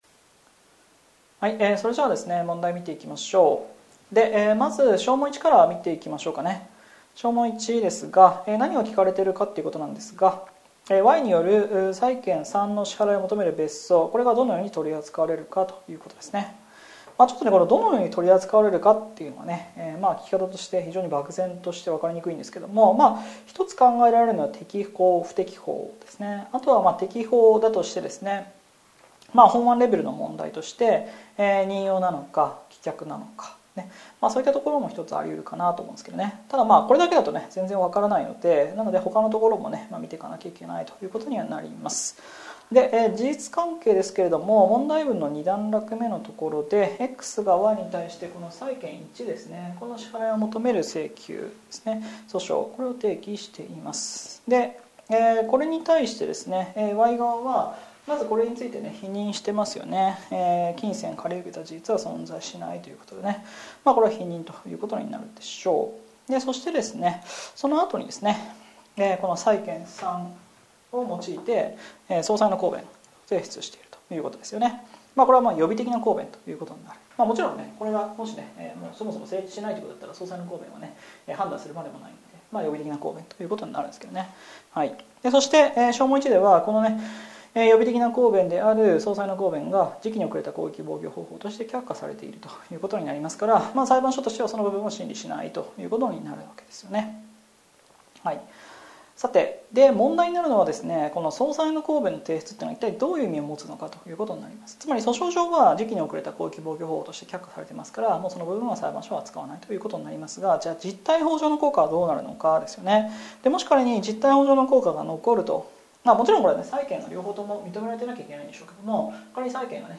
解説音声